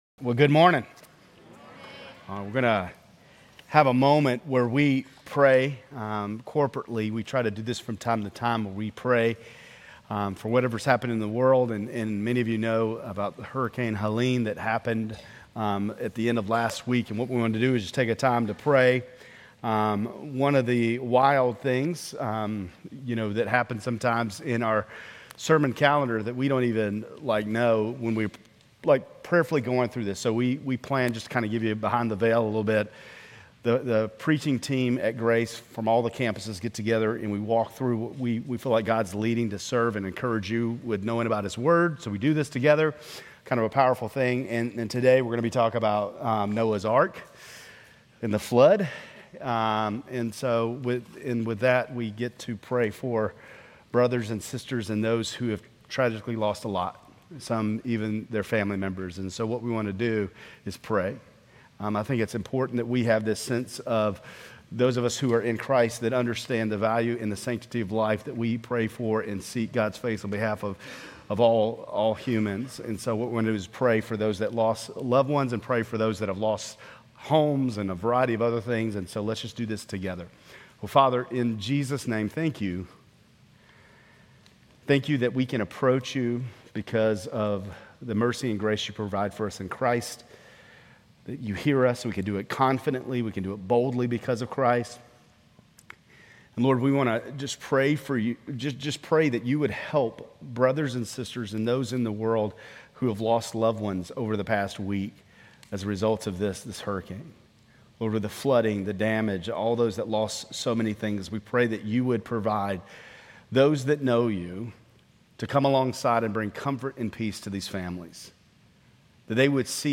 Grace Community Church Lindale Campus Sermons Genesis 6:5 - Noah Sep 29 2024 | 00:30:52 1x 00:00 / 00:30:52 Subscribe Share RSS Feed Share Link Embed